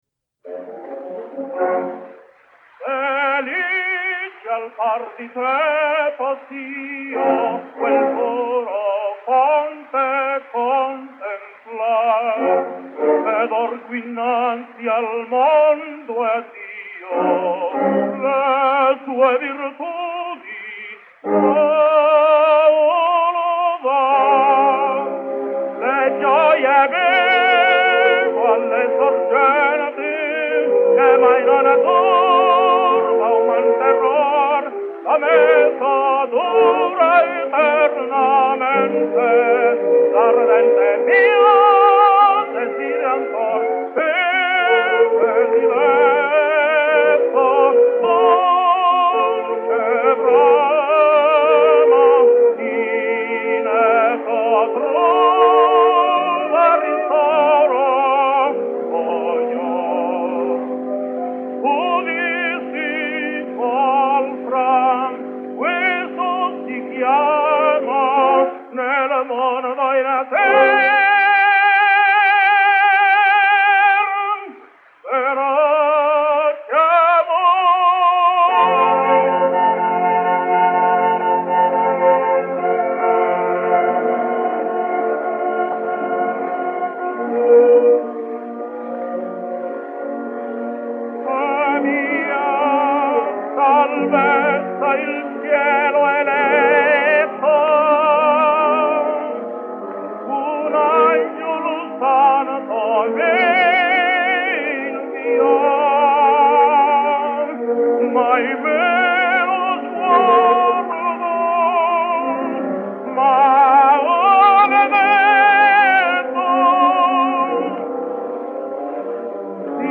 Spanish Tenor.
You will be hard put to notice any deterioration in the voice, a remarkable tribute to a man, who had been singing all over the world during that period.